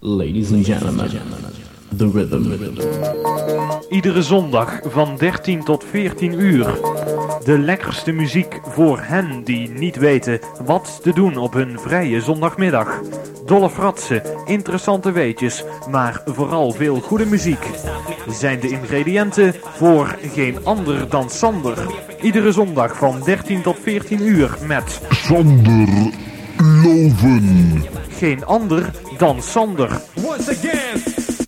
Reclamespot